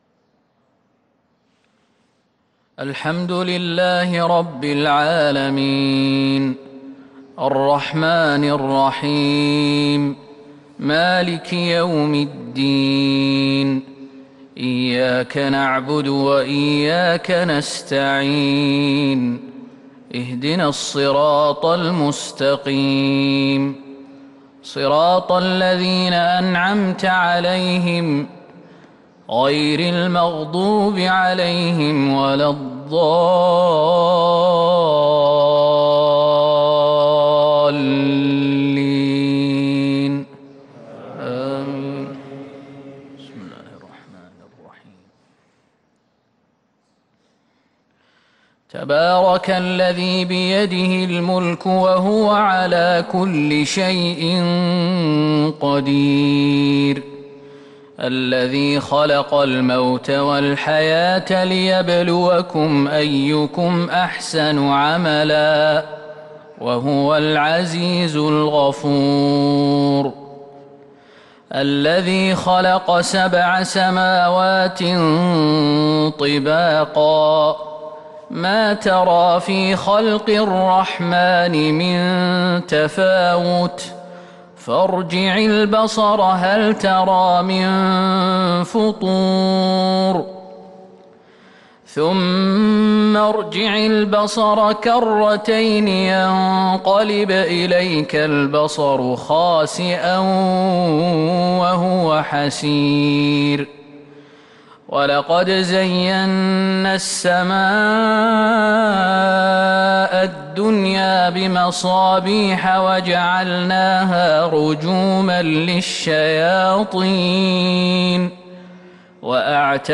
صلاة الفجر للقارئ ياسر الدوسري 24 ربيع الأول 1443 هـ
تِلَاوَات الْحَرَمَيْن .